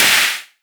edm-clap-24.wav